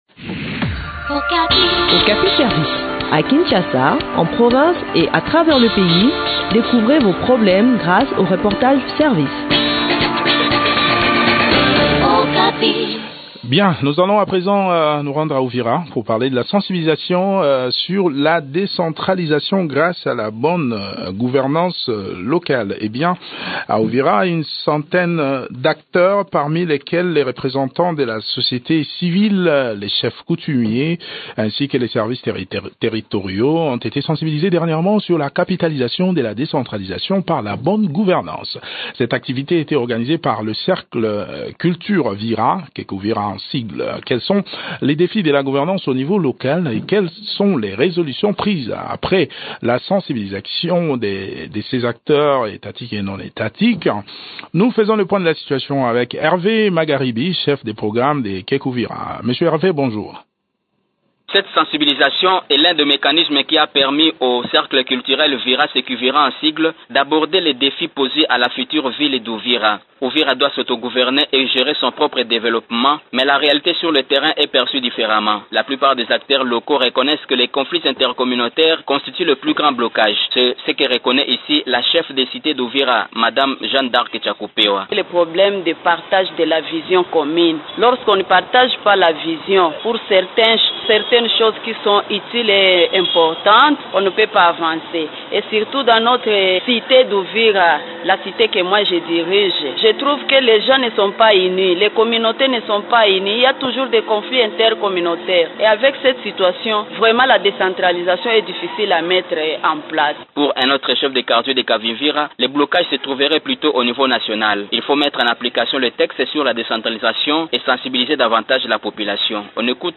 Selon le Cercle culture Vira (CECUVIRA), ce projet vise à promouvoir les notions de la décentralisation à la base. Le point sur l’organisation de cette séance de sensibilisation dans cet entretien